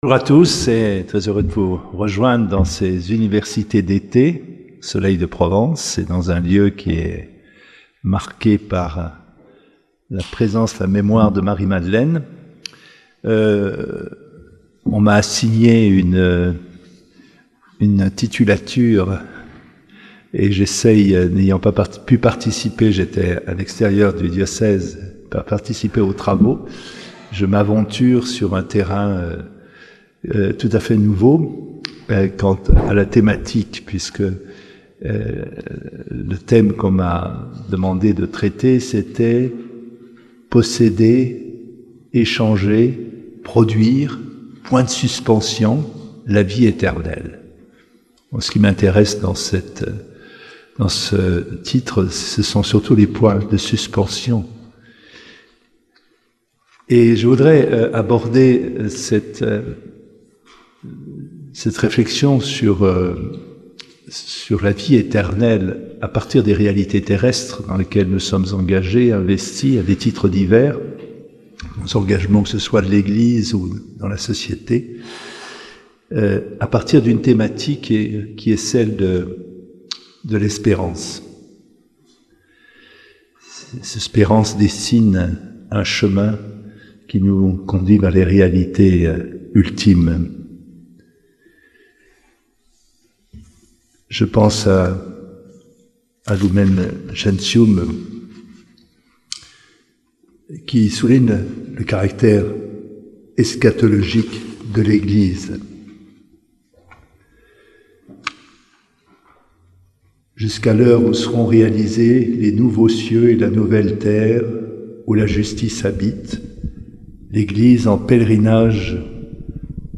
Ste Baume. Université d'été avec Mgr Dominique Rey